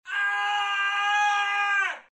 manscream